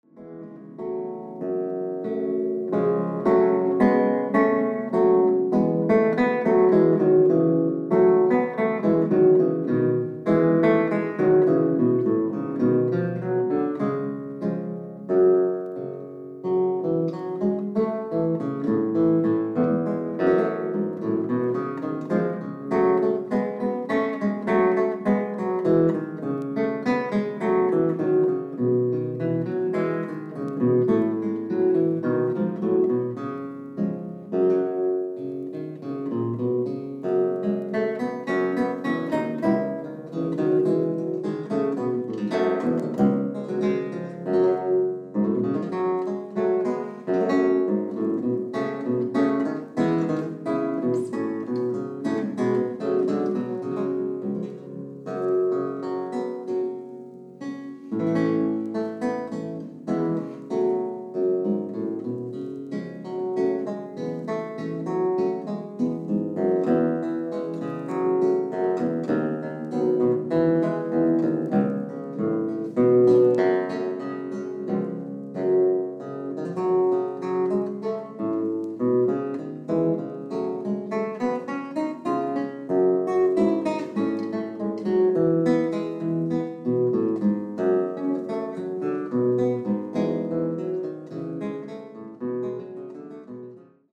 Jakob Lindberg "Italian Music For Lute & Chitarrone" LP
Artist : Jakob Lindberg